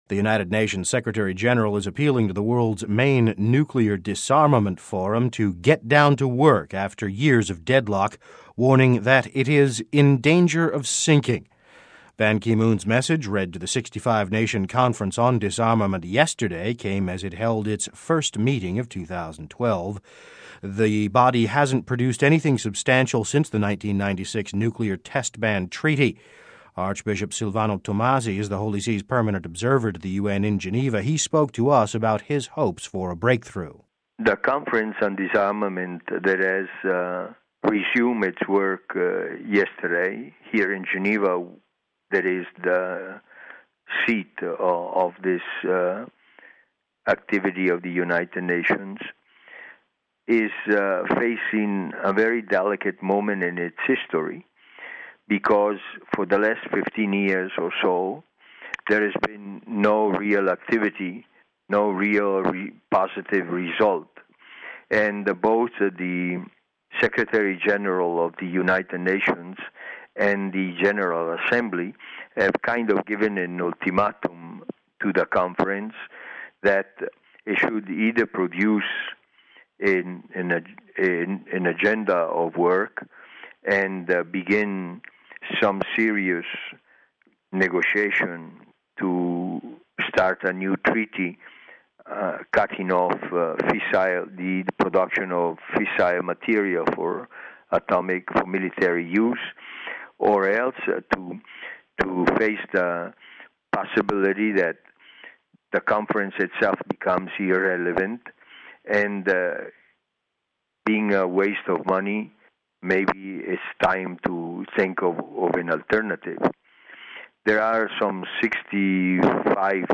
Archbishop Silvano Tomasi is the Holy See’s permanent observer to the UN in Geneva.
He spoke to us about his hopes for a breakthrough saying, “We hope that 2012 will break the impasse and opens up the possibility of a productive conference of disarmament.”